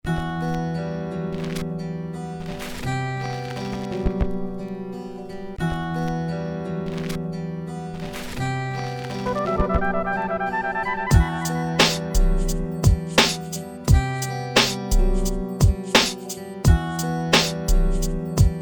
Минуса рэп исполнителей